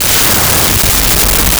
Explosion
explosion.wav